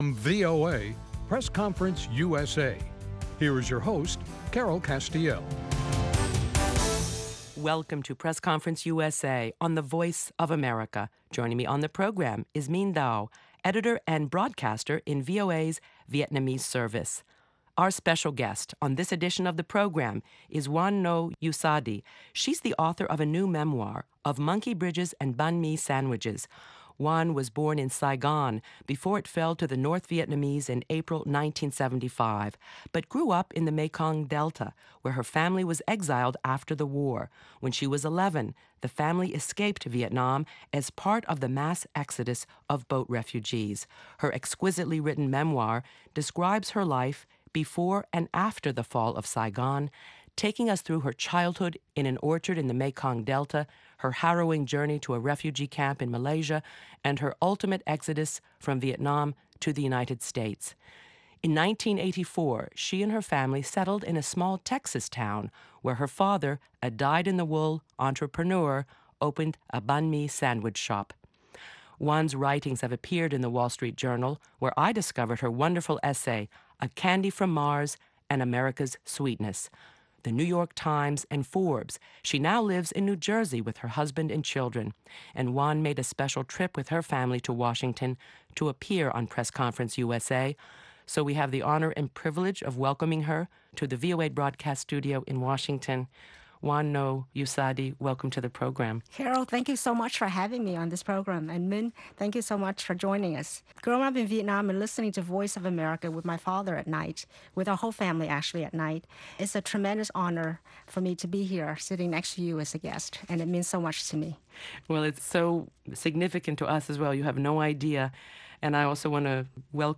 Encore: A Conversation